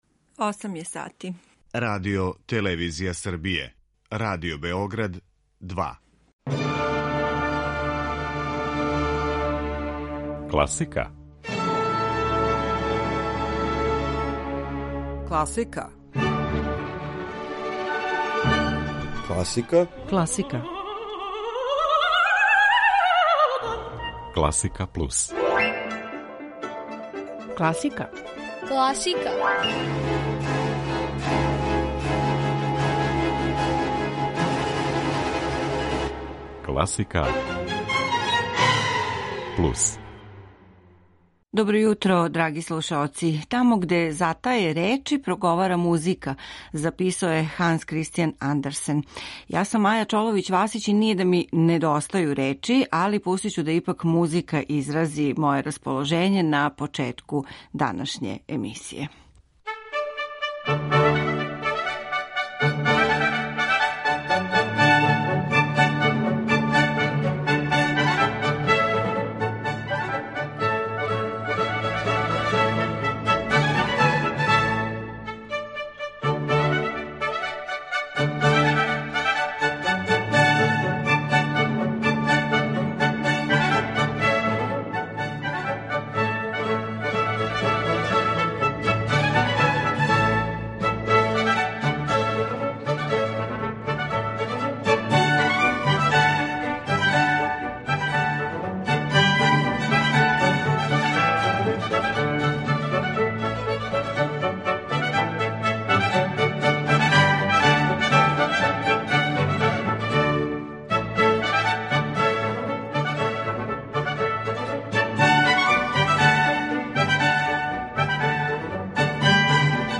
тенор
виолинисткиња
пијанисти